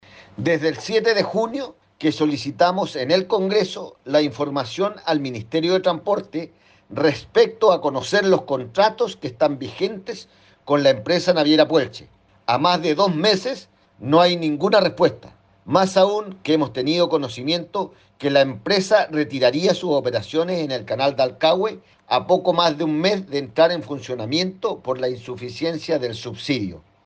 El diputado de la UDI por Chiloé, Fernando Bórquez, recordó que hace más de dos meses había solicitado conocer los alcances de los contratos que el Estado sostiene con la empresa.